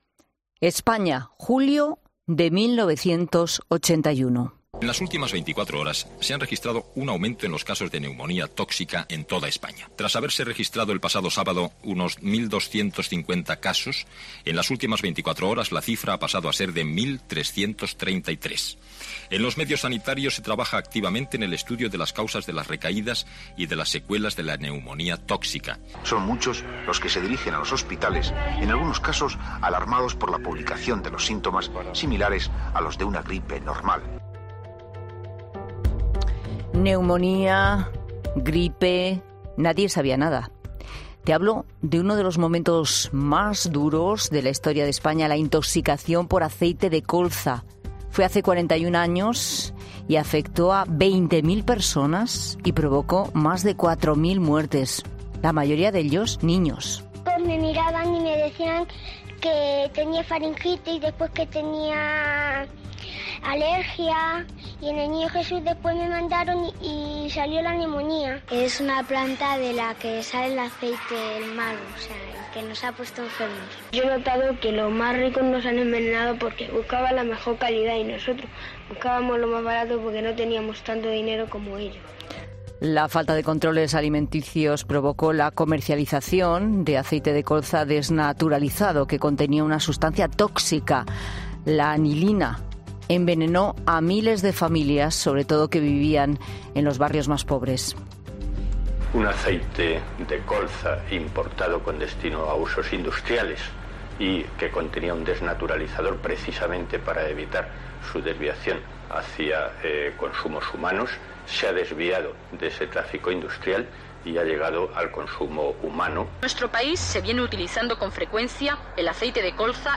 Un pediatra habla sobre el aceite de colza, que provocó la intoxicación de 20.000 personas en 1981